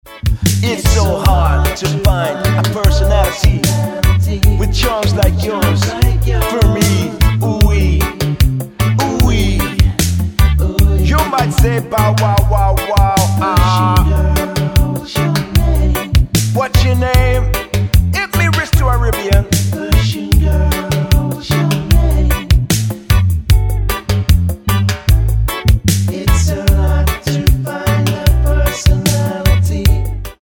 --> MP3 Demo abspielen...
Tonart:D plus Rap Vocals mit Chor